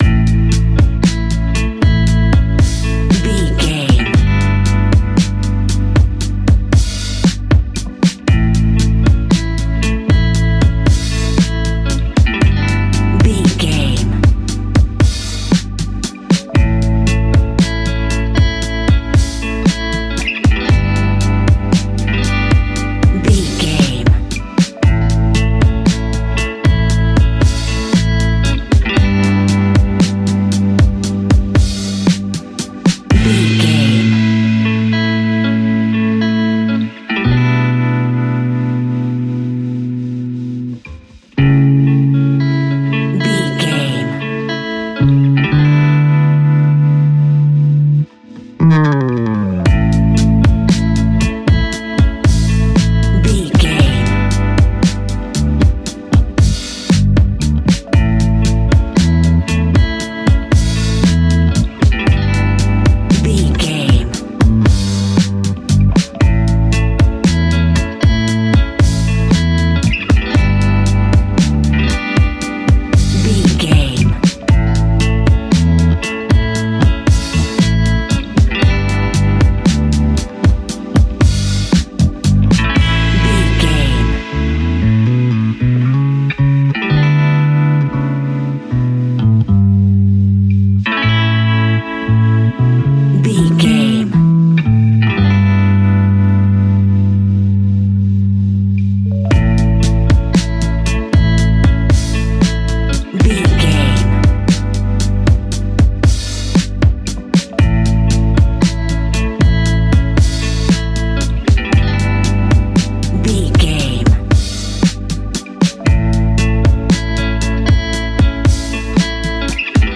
Ionian/Major
A♭
laid back
Lounge
sparse
new age
chilled electronica
ambient
atmospheric
instrumentals